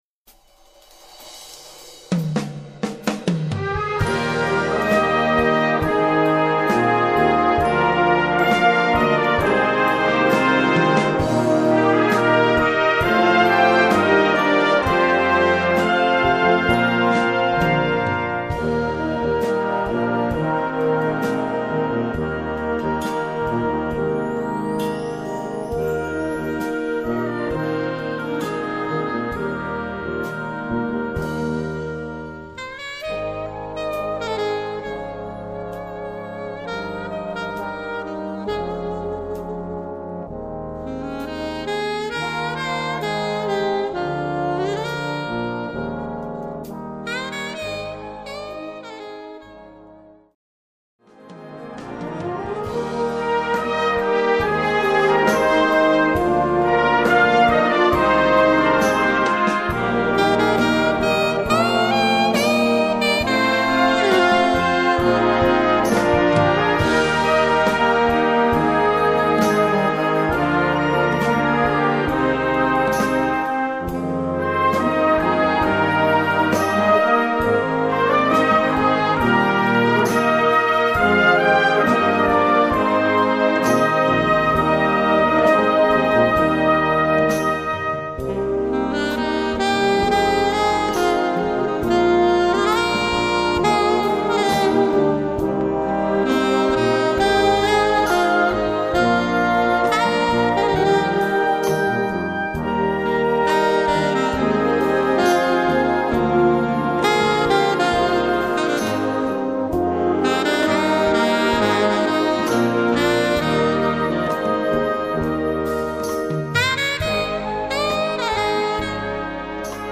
Besetzung: Blasorchester
Bb-Solo-Sopransaxophon